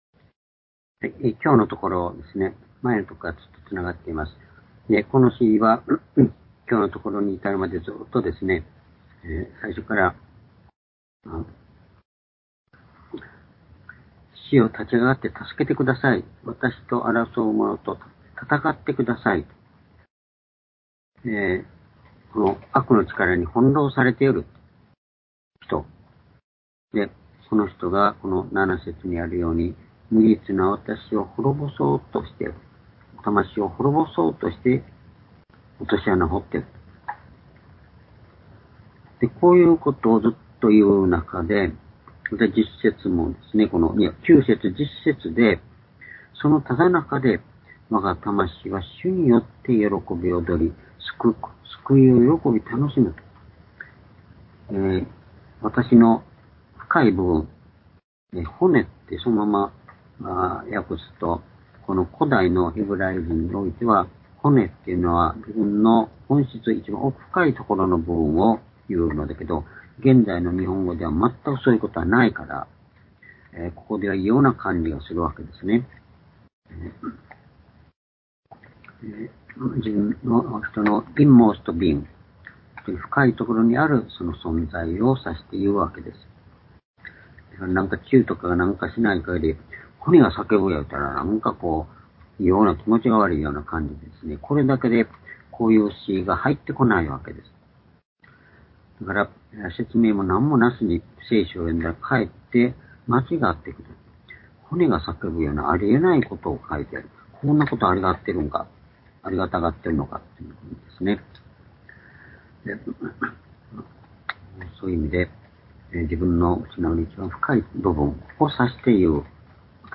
（主日・夕拝）礼拝日時 ２０２３年６月２０日（夕拝） 聖書講話箇所 「苦難から讃美への道」 詩篇35の22-25 ※視聴できない場合は をクリックしてください。